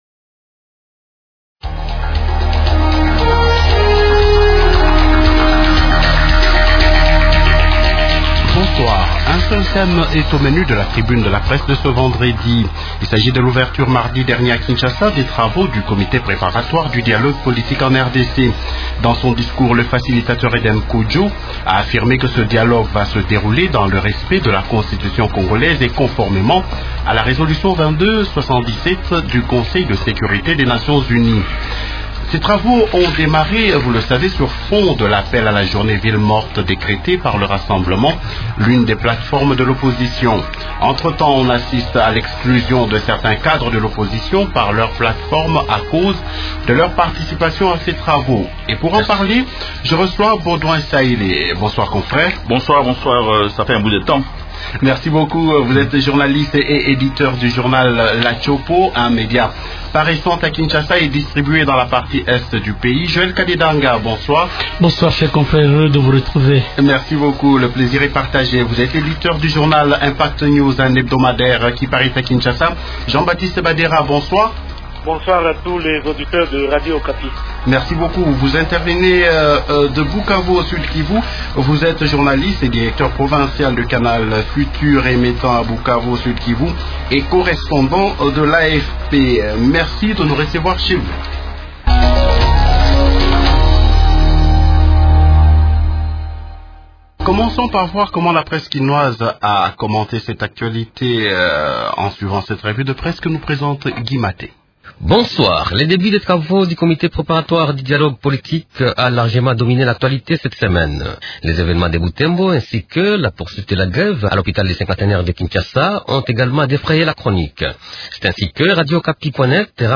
Un seuil seul thème est au centre de débat au cours de l’émission de cette semaine.